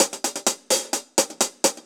UHH_AcoustiHatB_128-01.wav